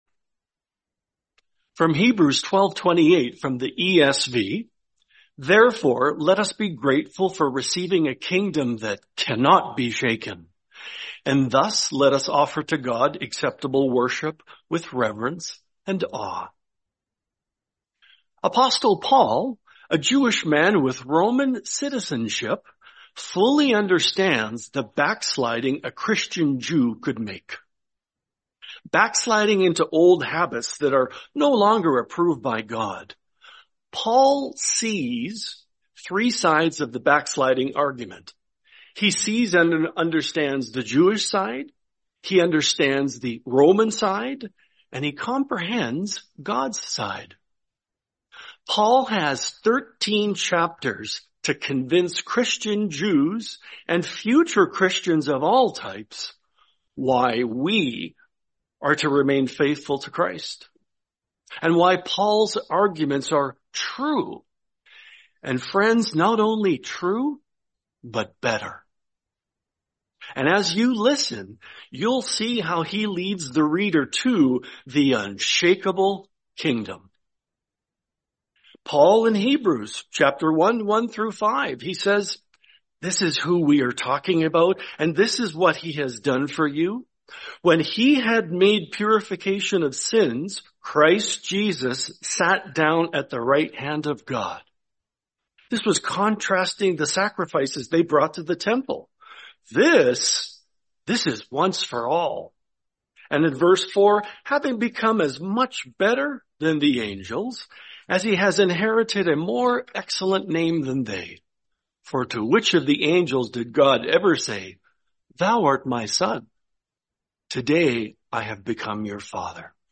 Series: 2025 Milwaukee Convention